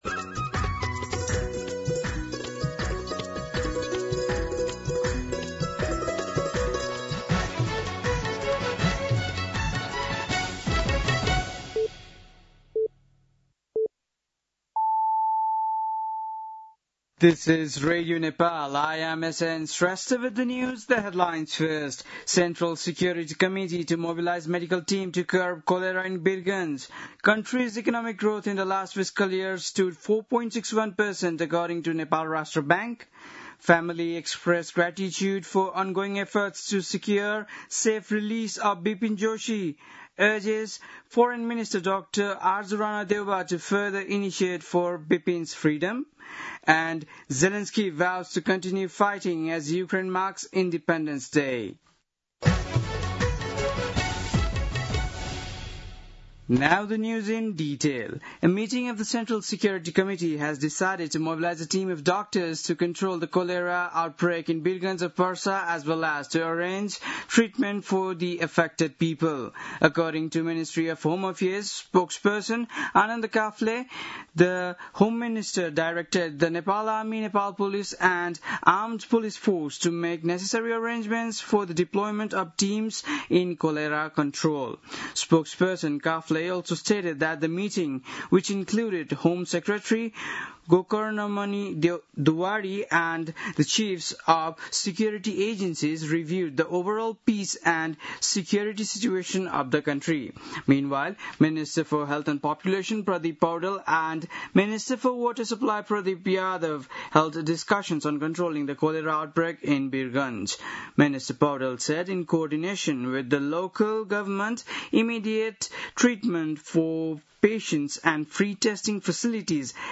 बेलुकी ८ बजेको अङ्ग्रेजी समाचार : ८ भदौ , २०८२
8-pm-english-news-5-8.mp3